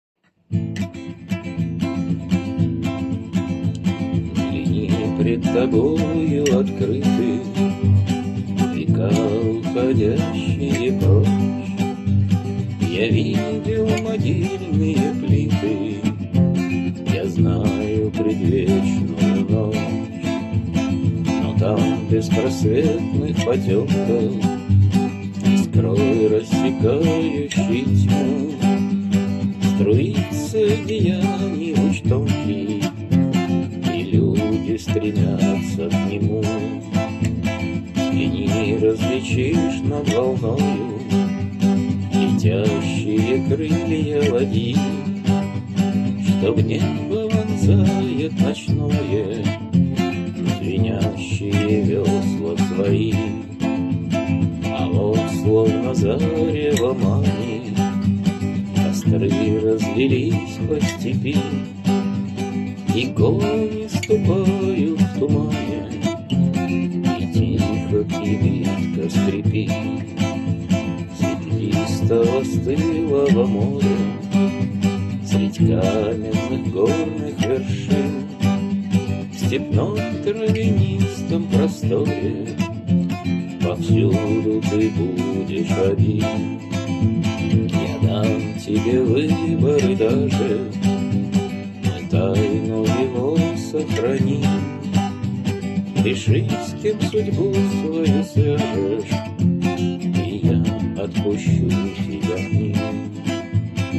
ved.mp3 (1625k) Пролог. Ария Веда